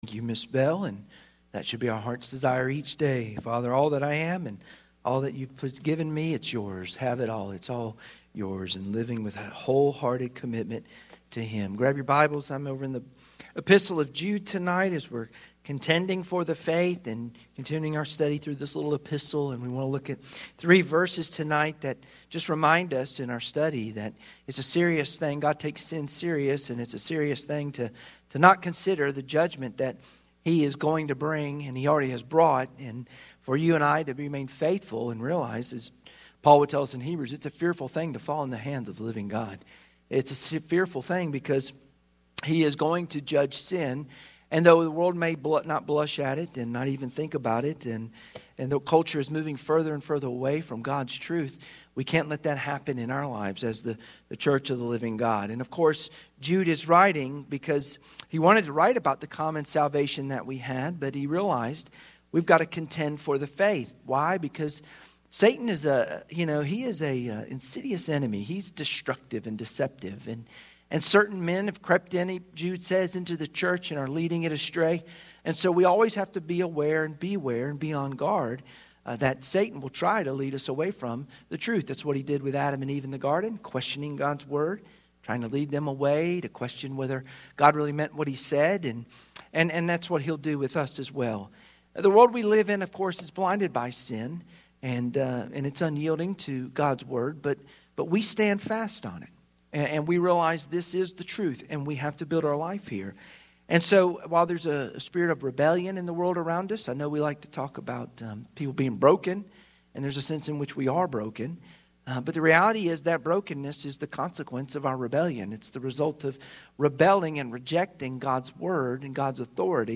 Jude 5-7 Service Type: Sunday Evening Worship Share this